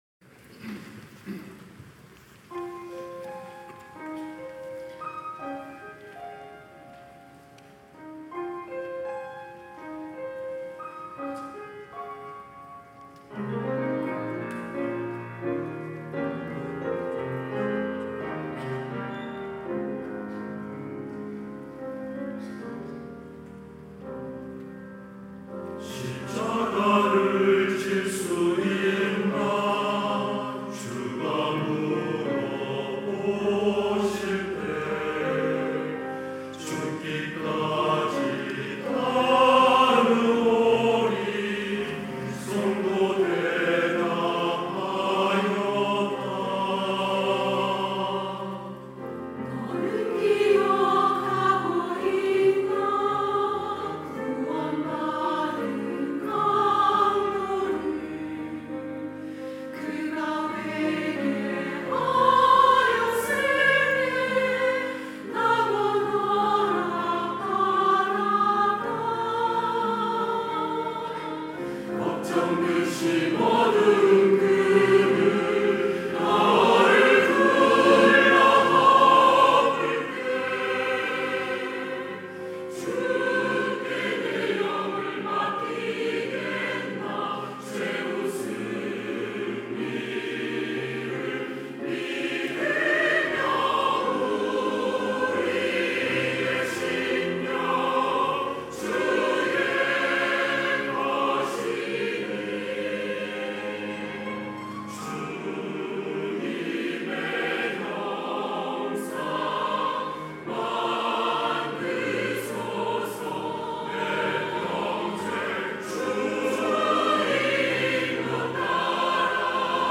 찬양대 할렐루야